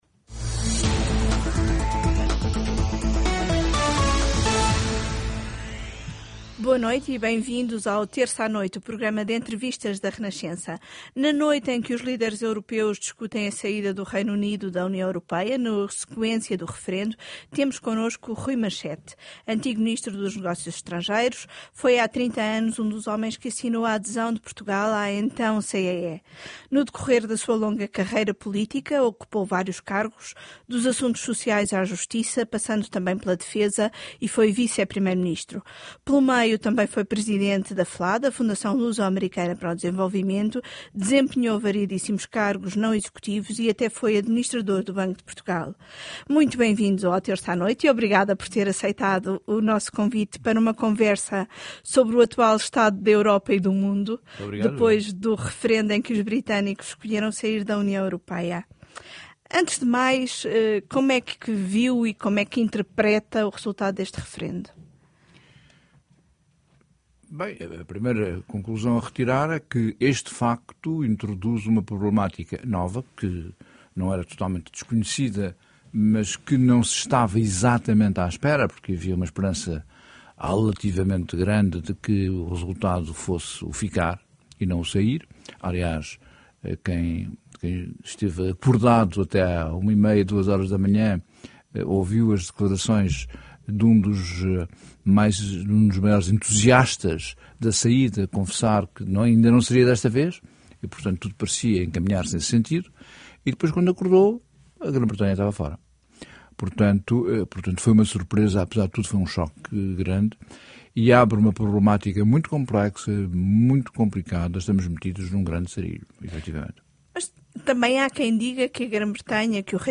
Em entrevista ao programa “Terça à Noite” da Renascença, Machete alerta que só oferendo esperança e solidariedade será possível contrariar o perigo de desagregação que considera existir.